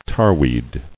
[tar·weed]